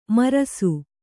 ♪ marasu